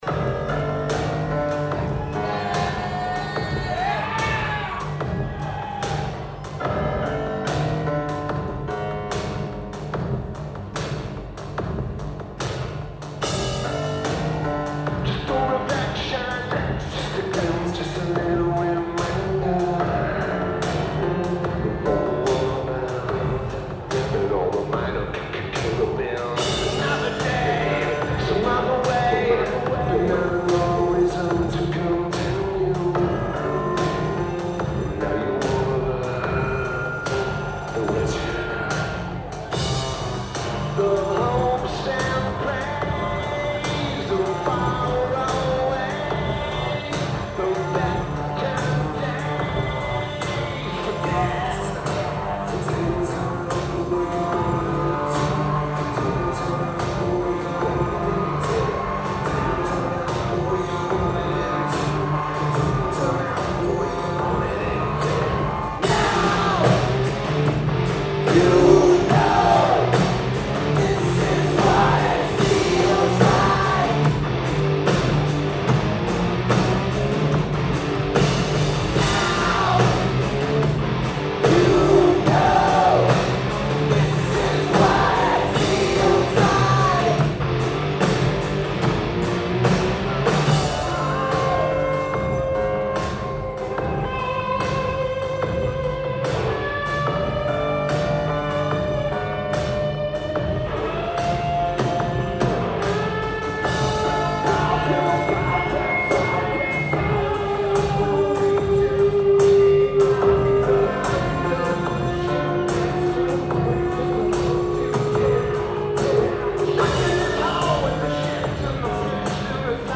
Austin, TX United States
Lineage: Audio - AUD (CSCs + BB + Zoom H2N)